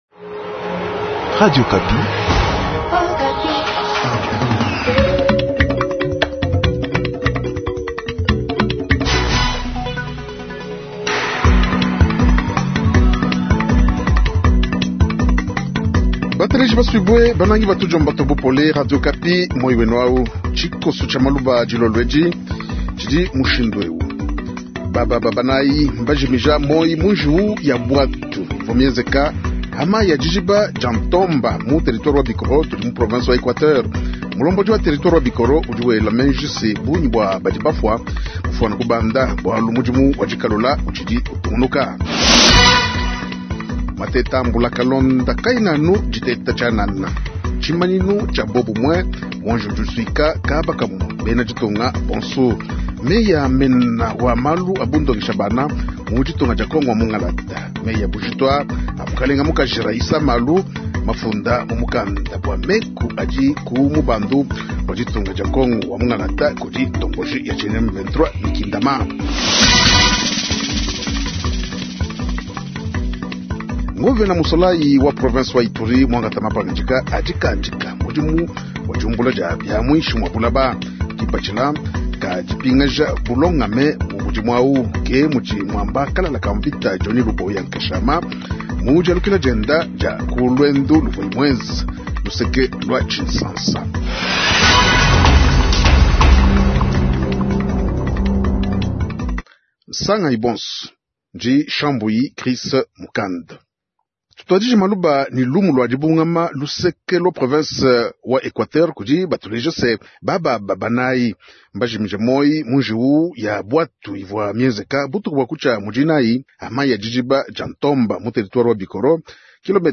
Journal tshiluba soir vendredi 29 aout 2025